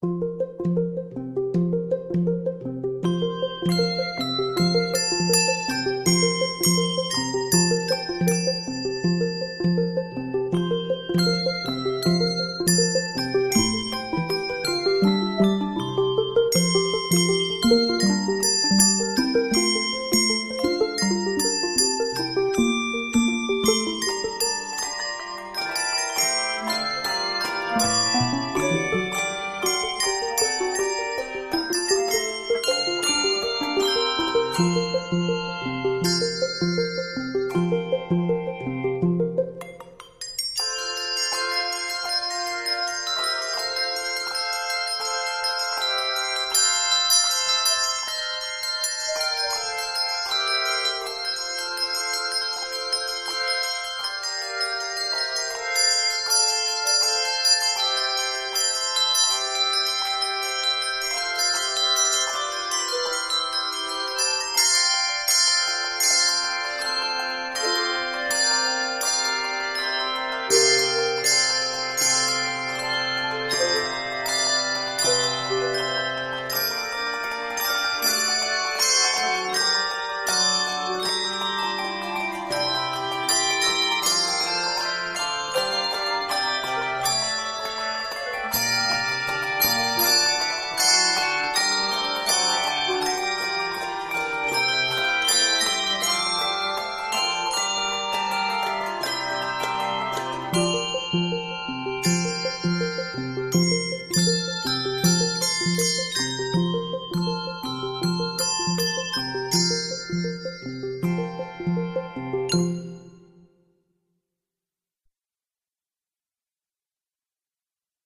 rhythmic and fun arrangement